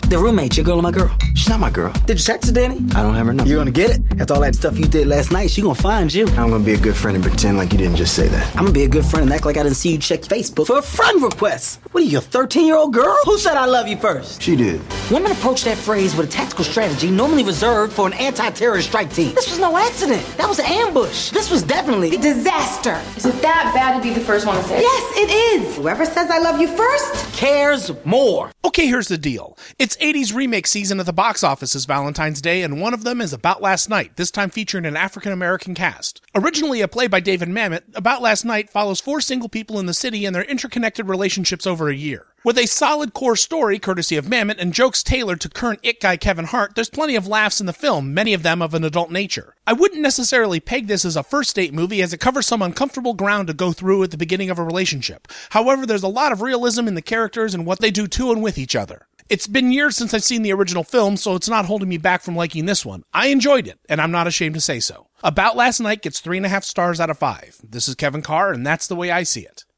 About Last Night’ Movie Review